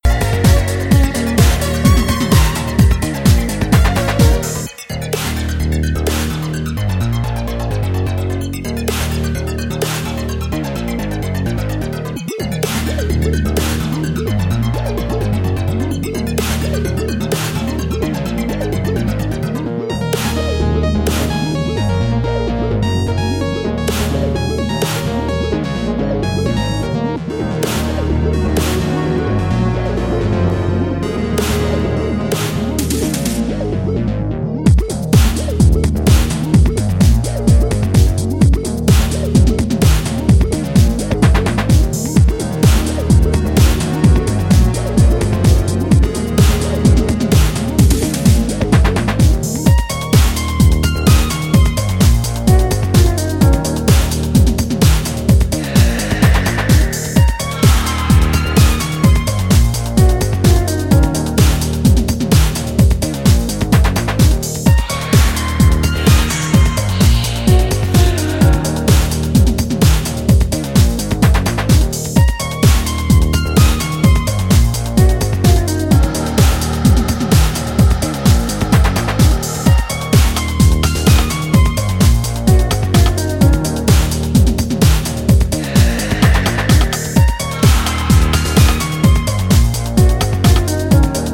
Classy cuts glittering with originality and outright funk.